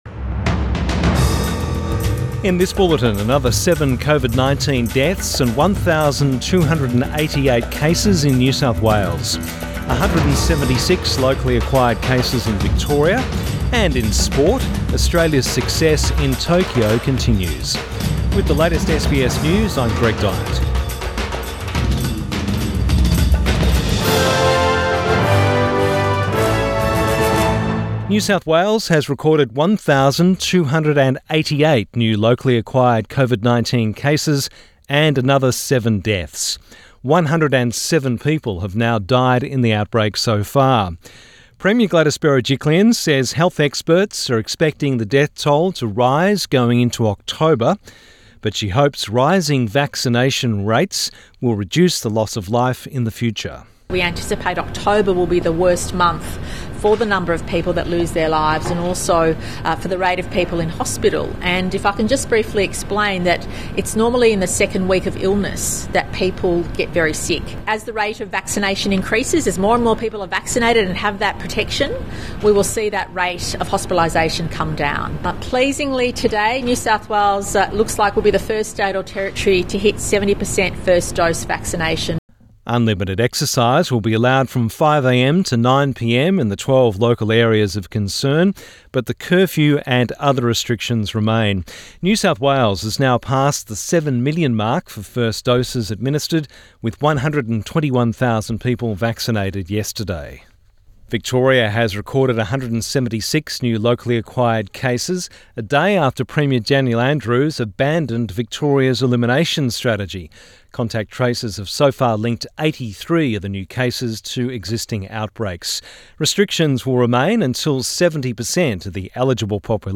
Midday bulletin 2 September 2021